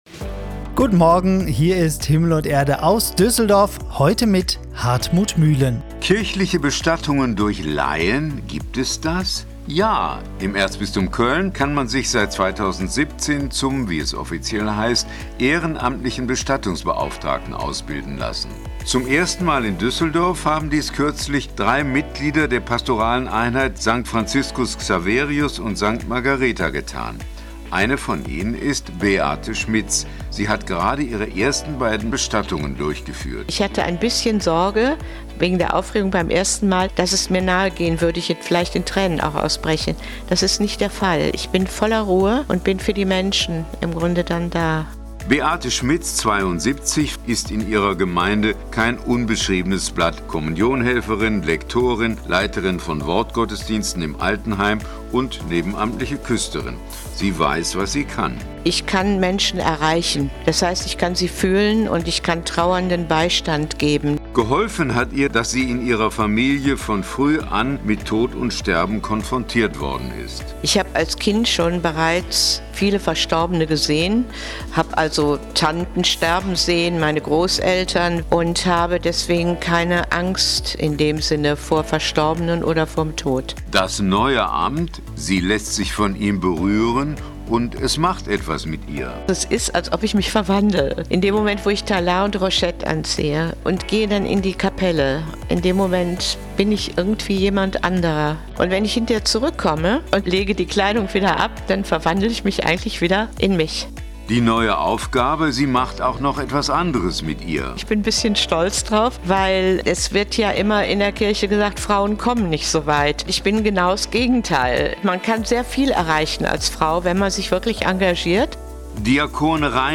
Interview zum ehrenamtlichen Bestattungsdienst